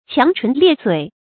強唇劣嘴 注音： ㄑㄧㄤˊ ㄔㄨㄣˊ ㄌㄧㄝ ˋ ㄗㄨㄟˇ 讀音讀法： 意思解釋： 謂說話厲害，不肯讓人。